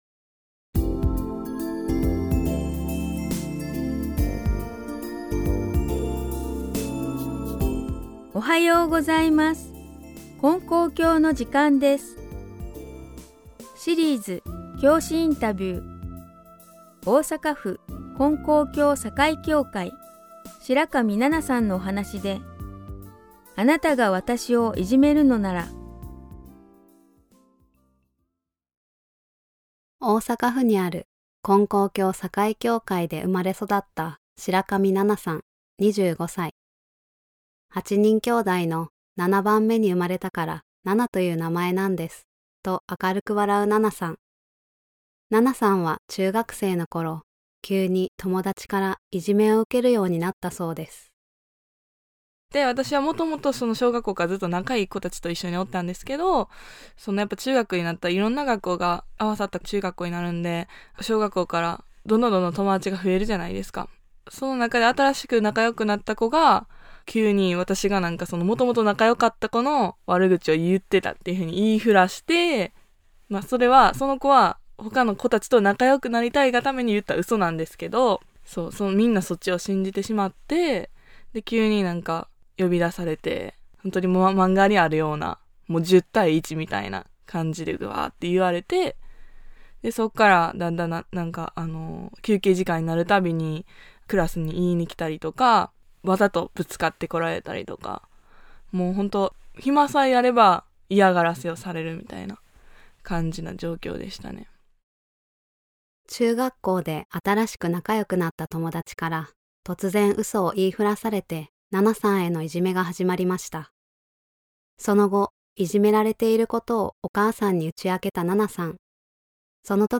●教師インタビュー